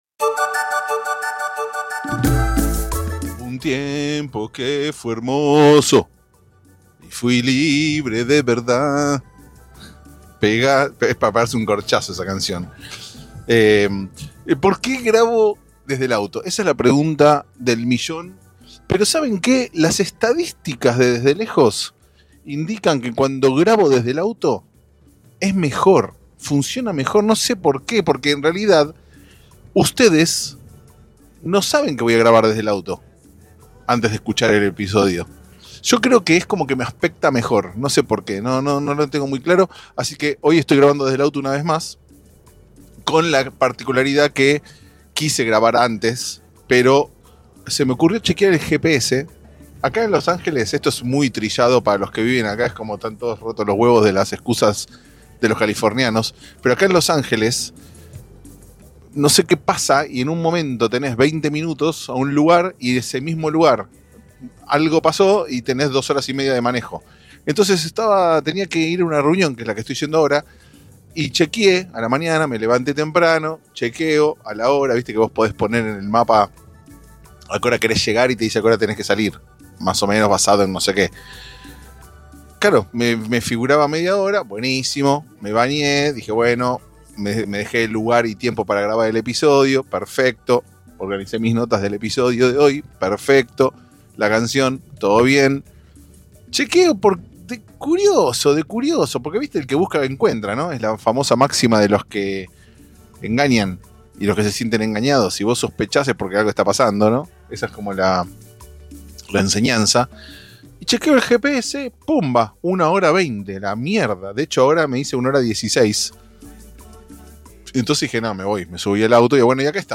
Acá estamos pues entonces, cantando a viva voz, jugando asincrónicos, hablando de lo importante.